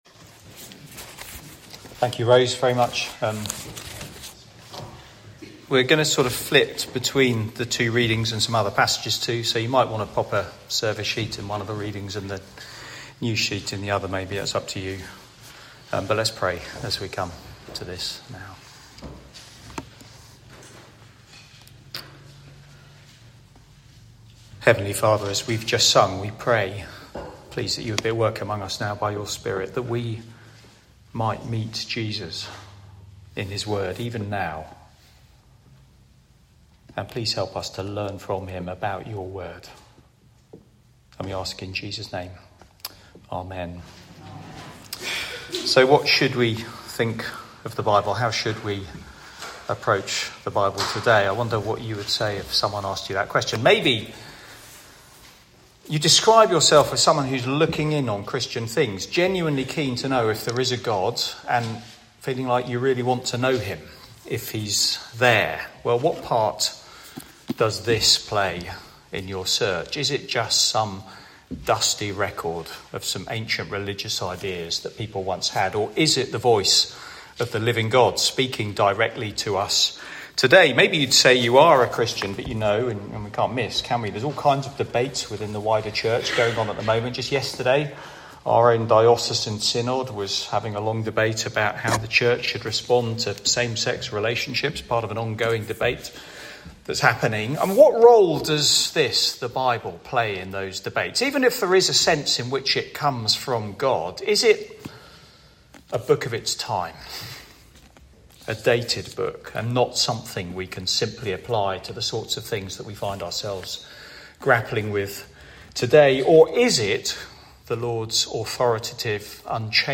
Media for Sunday Evening on Sun 15th Jun 2025 18:00
The Bible - Jesus' view Sermon